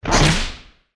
target_launch.ogg